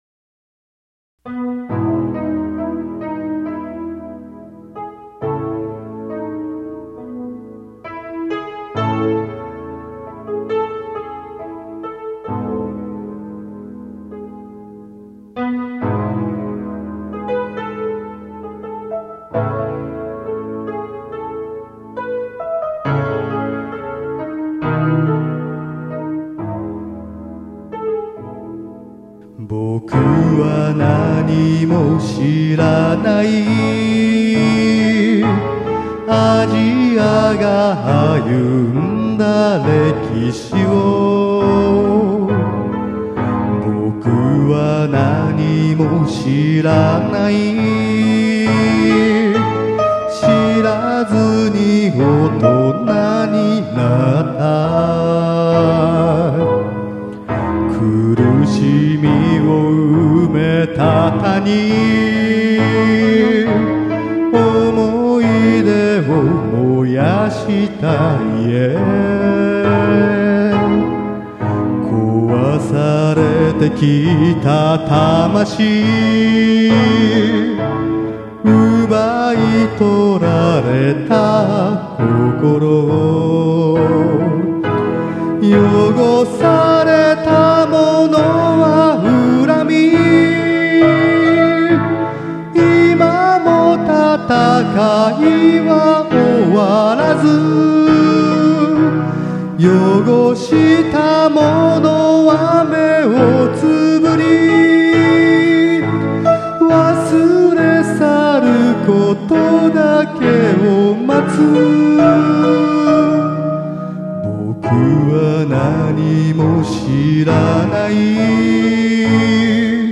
歌が下手です（汗）